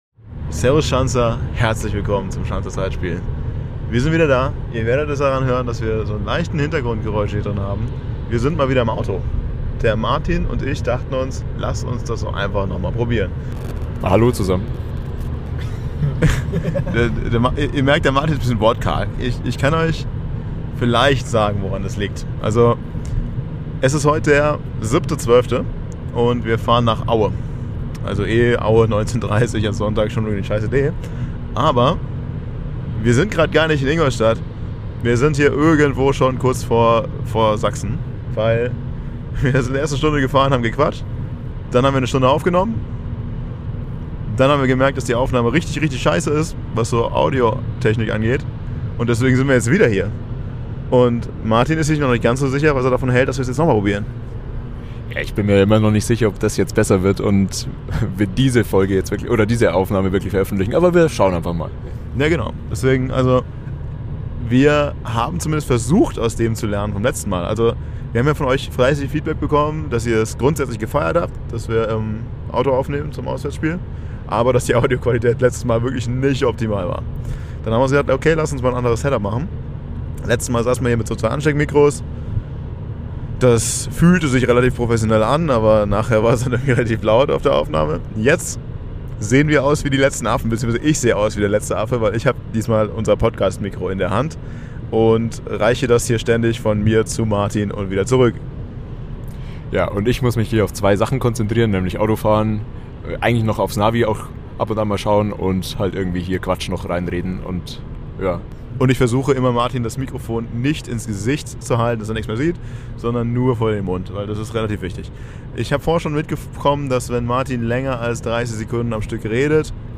Das Audio-Setup ist hoffentlich verbessert, wenngleich Hintergrundgeräusche bei einer Aufnahme im Auto natürlich nie gänzlich zu vermeiden sind. Diskutiert wird über so manche "Nebenkriegsschauplätze", ehe wir auf die sportliche Lage und das 6-Punkte-Spiel in Aue eingehen. Am Ende gibt es ein ereignisreiches Spiel zu besprechen, das in letzter Minute eine positive Wendung für die Schanzer bereithält.